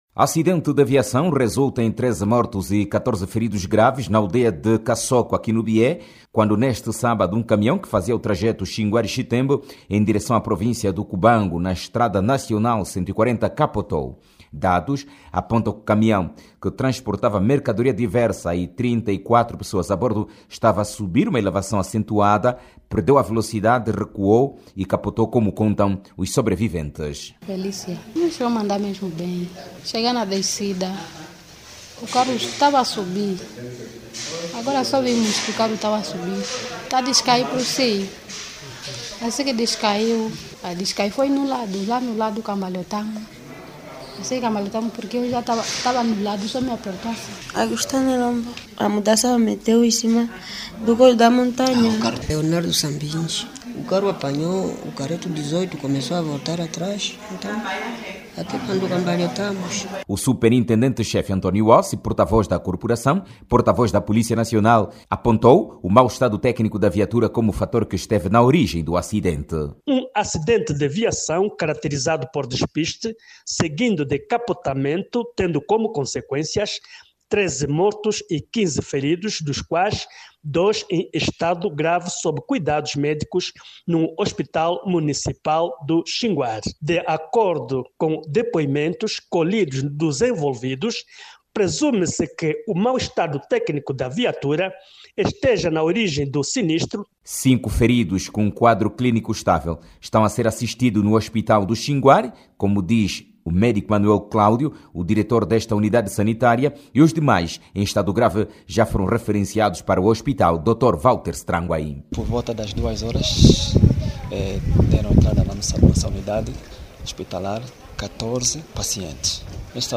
Acidente de viação ocorrido na madrugada de hoje, domingo(20), na Província do Bié, resultou nan morte de 13 pessoas. O acidente ocorreu quando o camião de carga, em que seguiam, saía do Chinguar para o Chitembo, despistou e capotou. Clique no áudio abaixo e ouça a reportagem